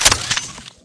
ignitemg_reload.wav